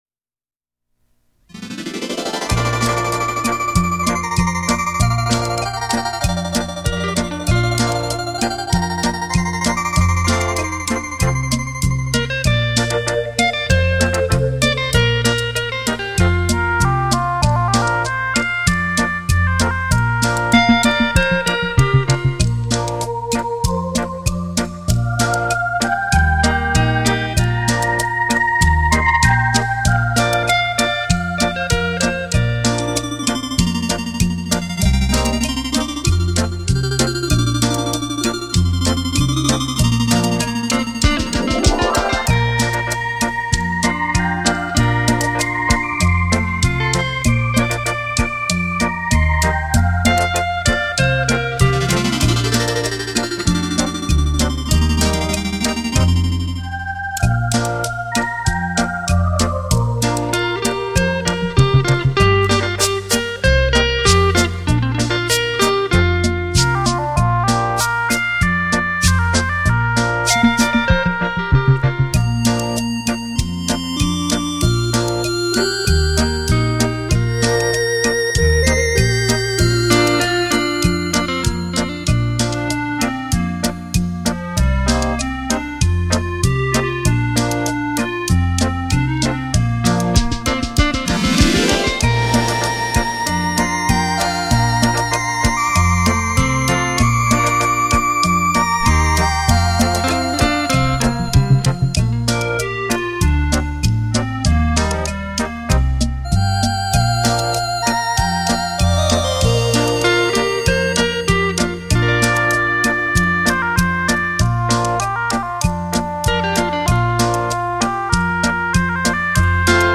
原音重现·超立体现场演奏